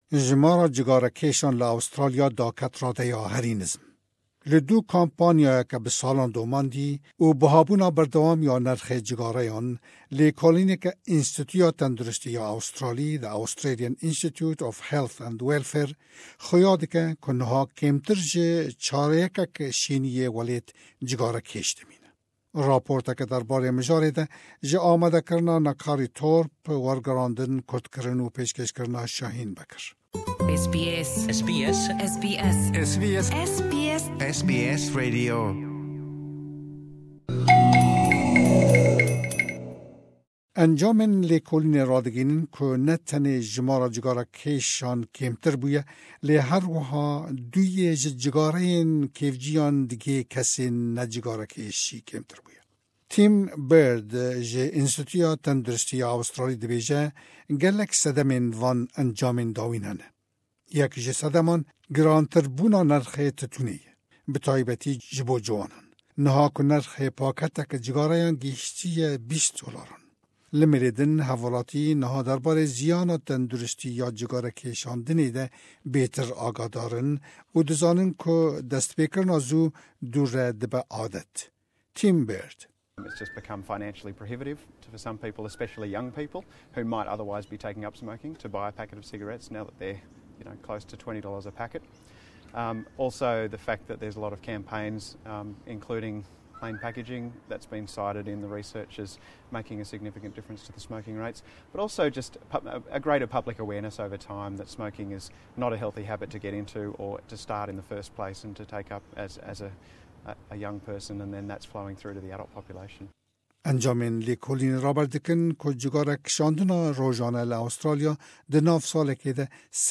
Jimara cigarekêshan li Australyan her di daketinê de ye. Ev raport sedeman xuya dike.